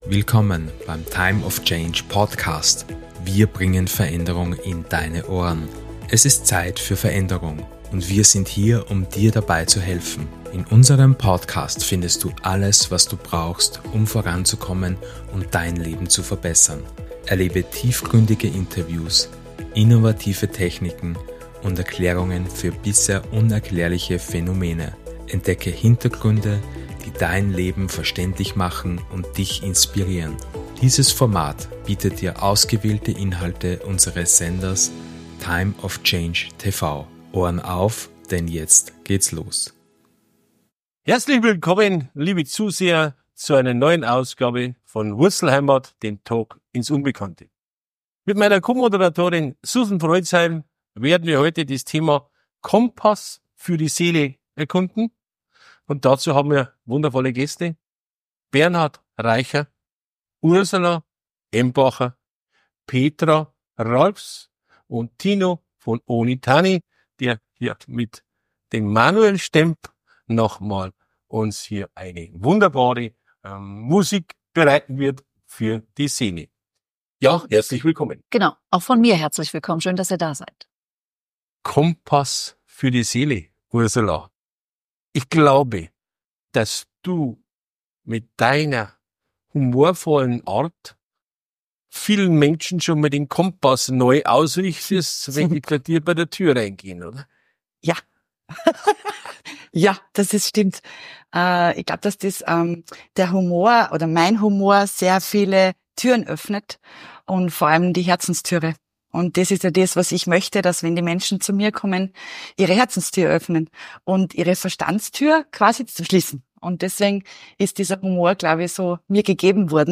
Freue Dich auf eine Sendung voller Inspiration, Humor und tiefgründiger Gespräche.